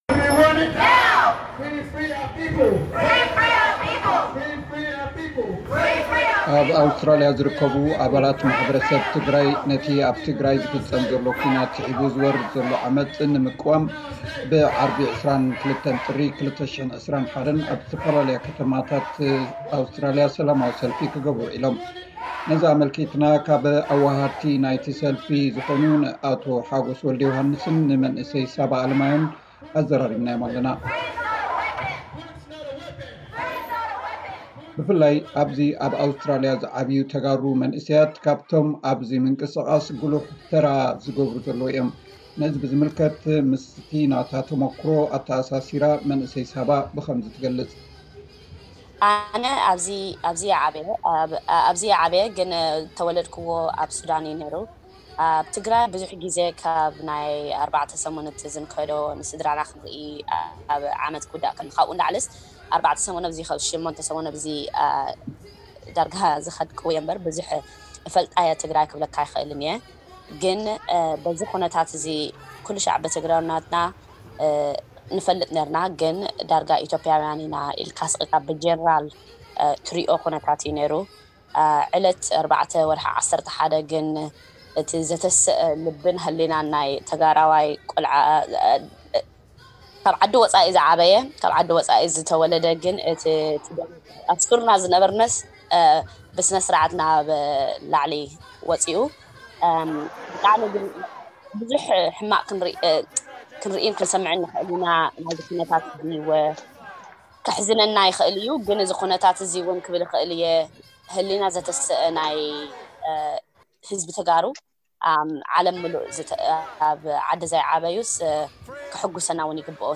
ኣብ ኣውስትራሊያ ዝነብሩ ተጋሩ ዝገበርዎ ሰላማዊ ሰልፊ ዝምልከት ዝርርብ